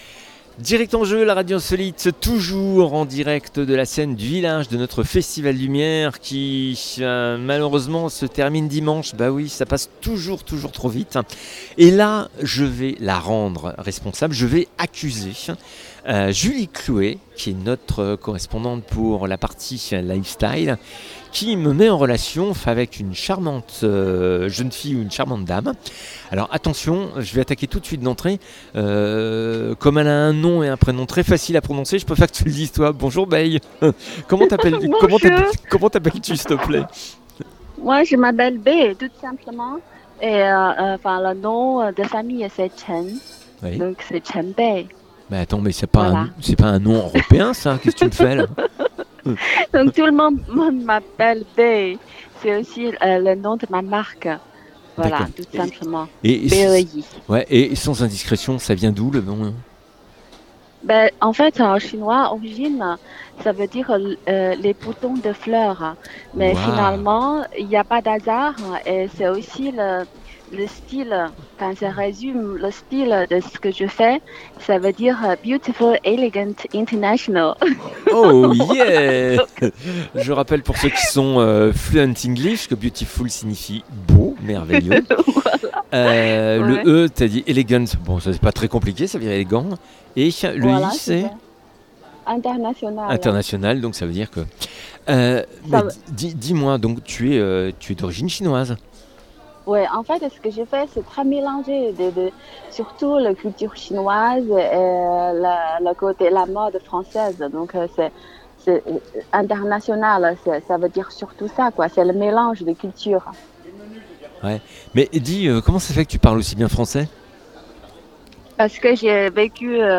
L'interview abordedes sujets autour de ses créations, de sa relation avec la France, de sa collaboration avec les enfants autistes…